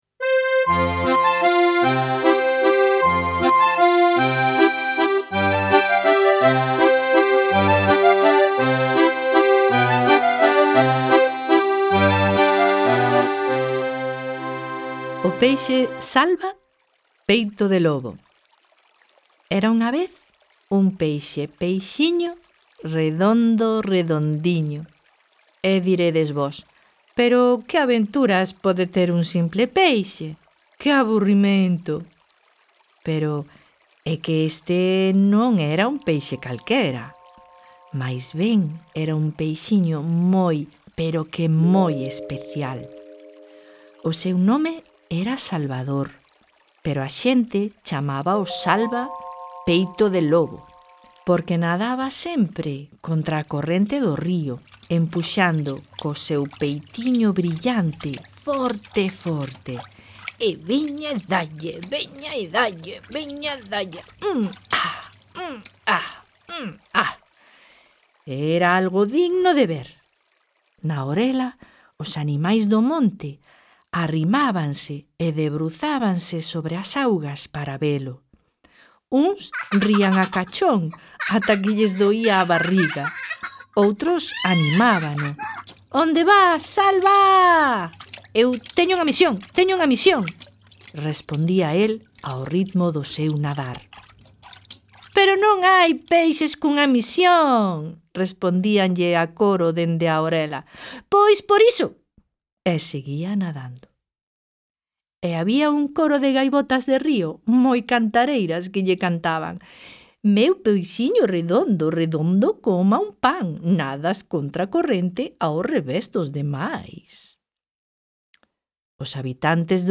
Audioconto: O peixe Salva, peito de lobo | Contiños para despertar versos
Peixe_Salva_audiocontos_con_efectos.mp3